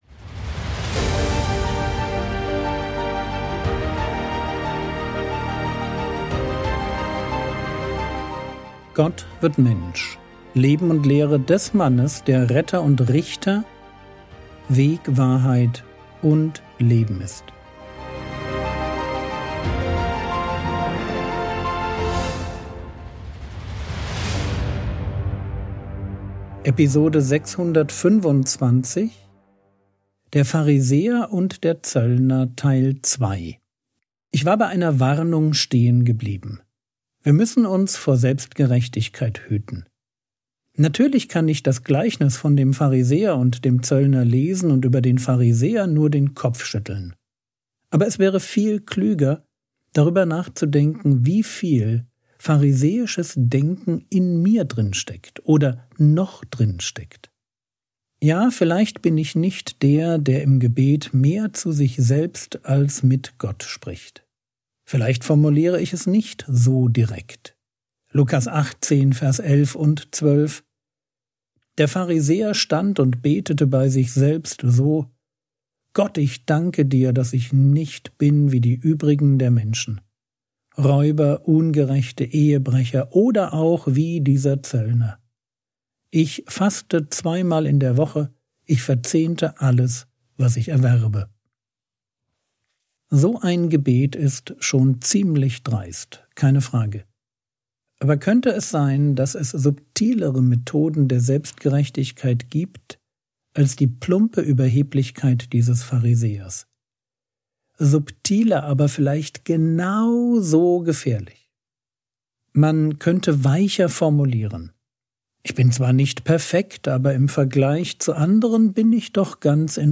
Episode 625 | Jesu Leben und Lehre ~ Frogwords Mini-Predigt Podcast